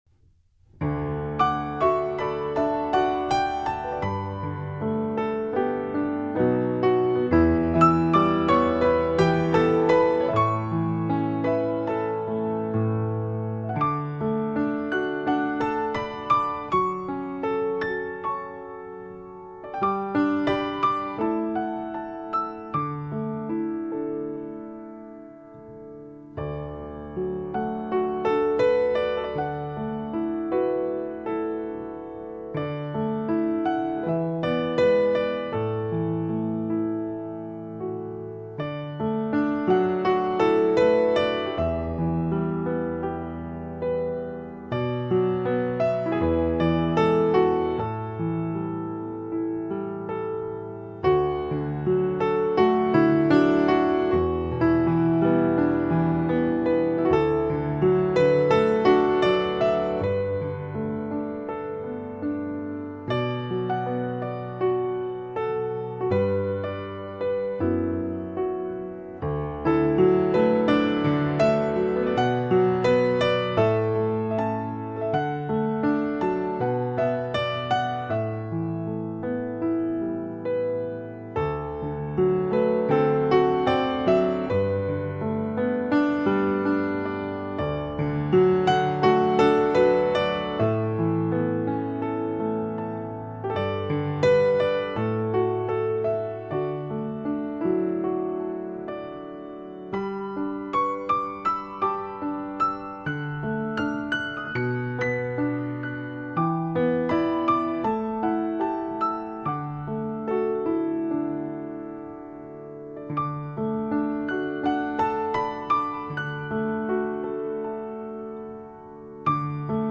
如歌琴声，淡淡幽情，唯美音符，思君如梦。自然传真，示范级三角名琴录音；晶莹剔透的美妙音符，聆听不曾离去的动人主旋律。